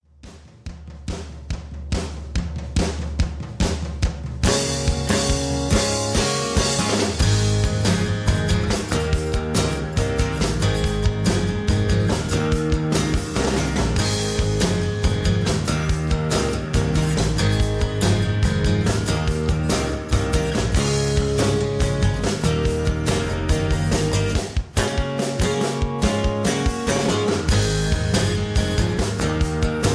backing tracks, karaoke